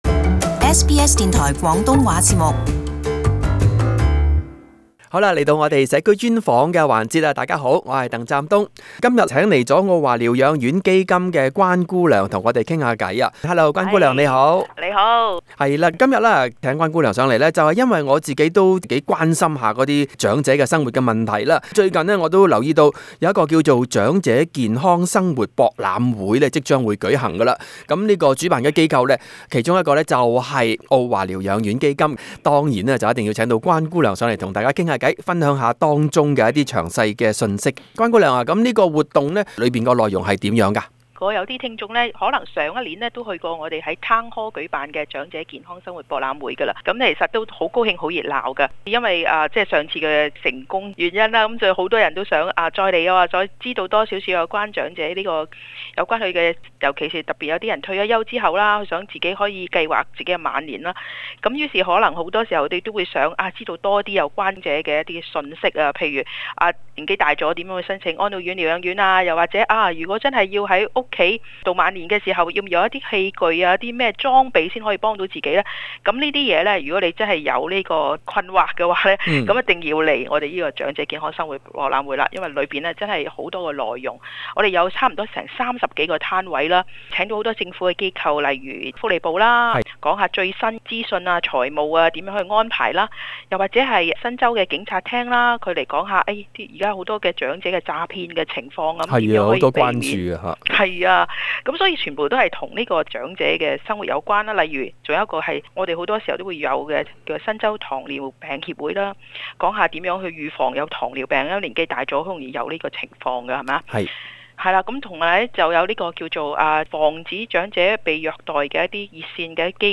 【社區專訪】 長者健康生活博覽會2019